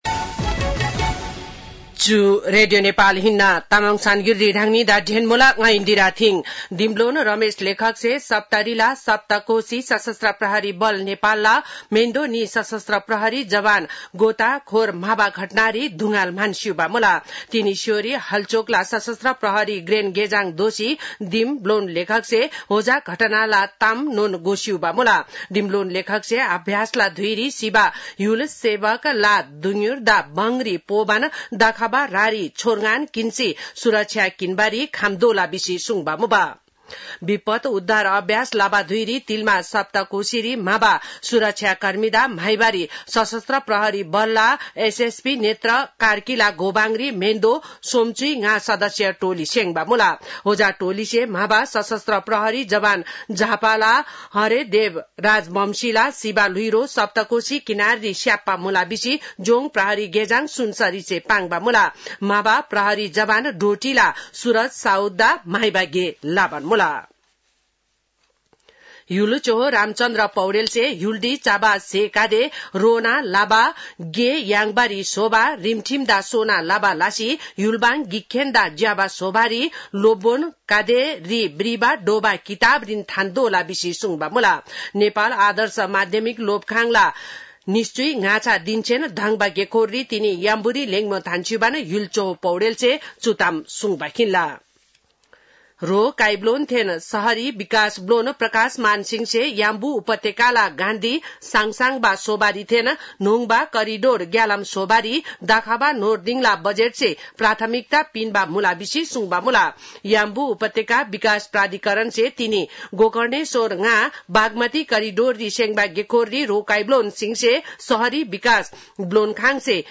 तामाङ भाषाको समाचार : २३ जेठ , २०८२
Tamang-news-2-23-1.mp3